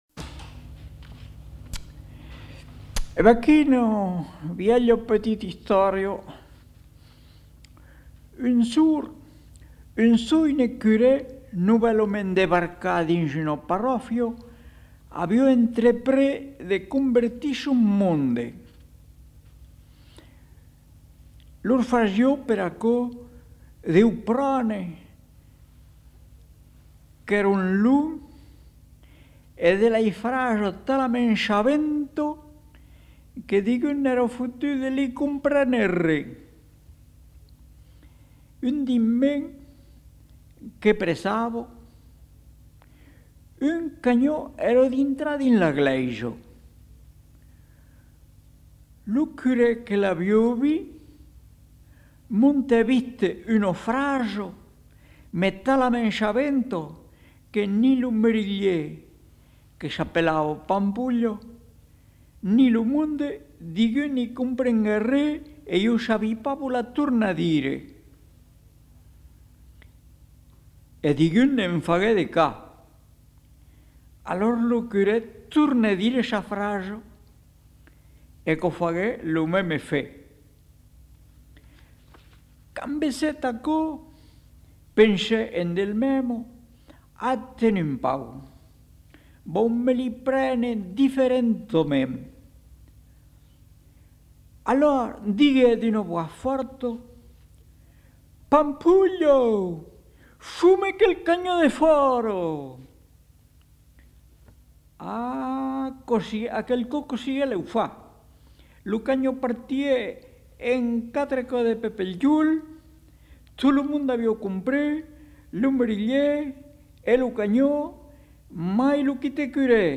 Aire culturelle : Périgord
Lieu : Lolme
Genre : conte-légende-récit
Effectif : 1
Type de voix : voix d'homme
Production du son : parlé